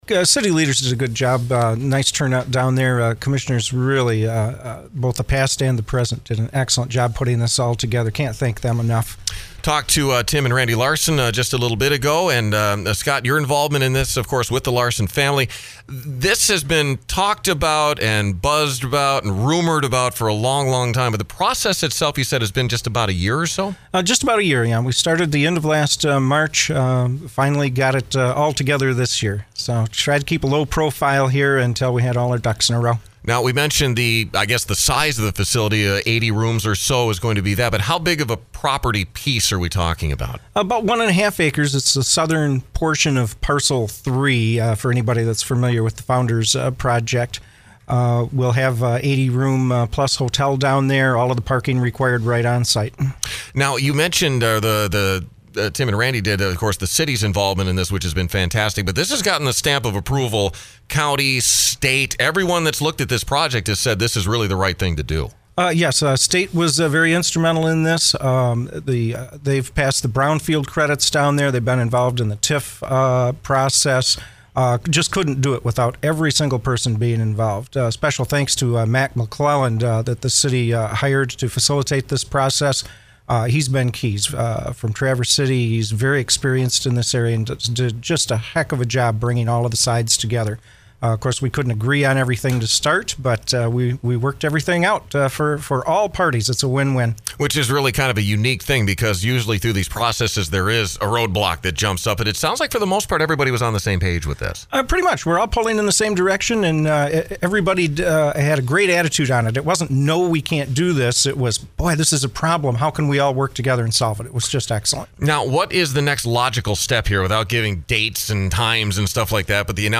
NewsOld Interviews Archive